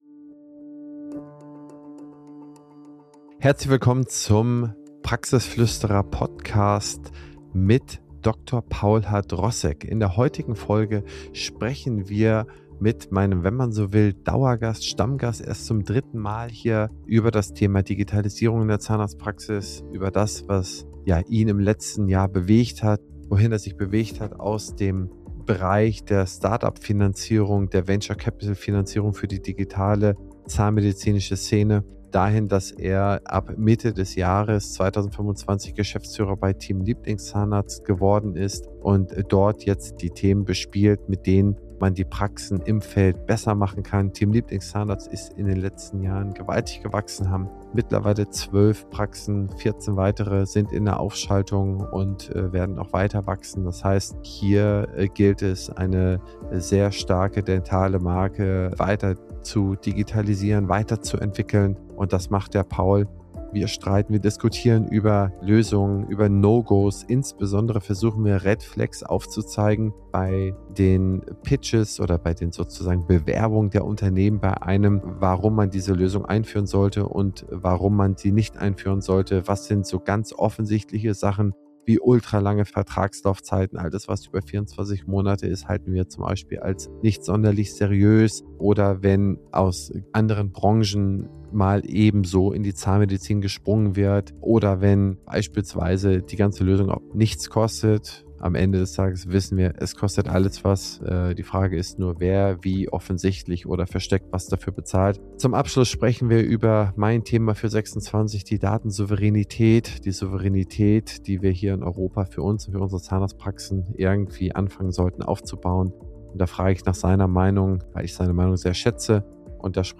Gemeinsam diskutieren sie, wie Innovationen entstehen, Investitionen sinnvoll eingesetzt werden – und vor allem, worauf Praxisinhaber achten sollten, wenn neue digitale Lösungen angeboten werden. Freut euch auf eine lebendige Diskussion voller Praxisbezug, ehrlicher Erfahrungen und handfester Tipps rund um Digitalisierung, Red Flags bei Software-Anbietern sowie den wichtigen Aspekt der Datensouveränität.